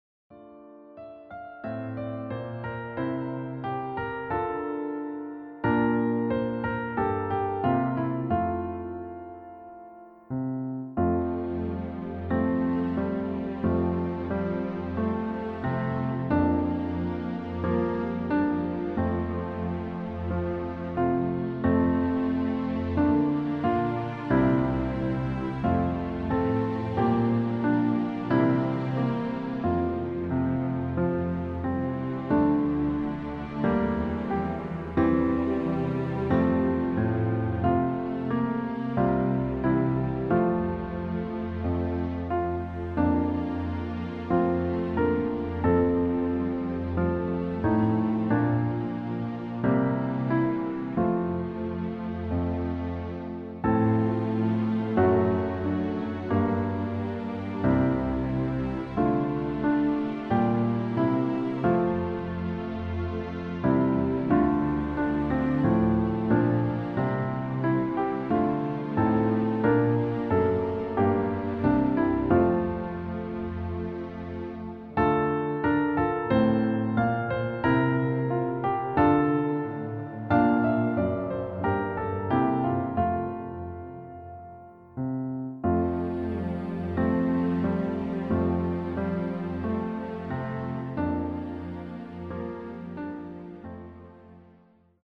• Tonart:  Db Dur, F Dur (weitere auf Anfrage)
• Art: Klavier Streicher Version
• Das Instrumental beinhaltet NICHT die Leadstimme
Klavier / Streicher